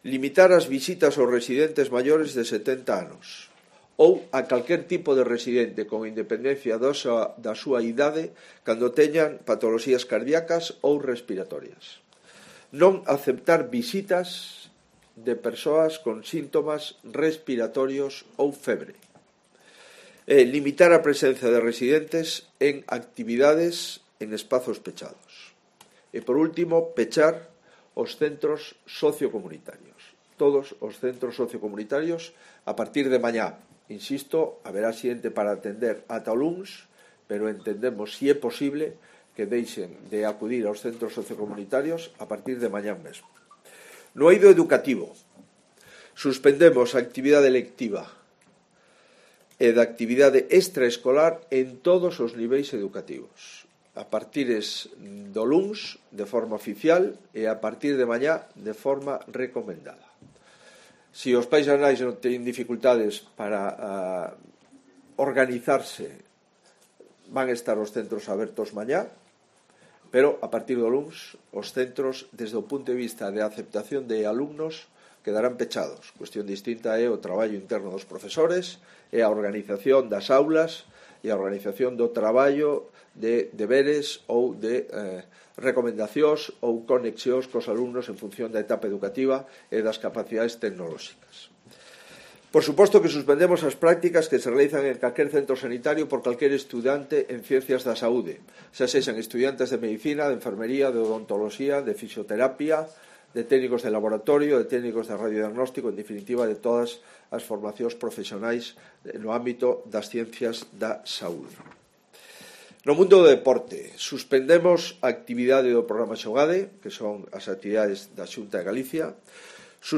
DECLARACIONES de Alberto Núñez Feijóo tras el Consello de Gobierno de la Xunta de Galicia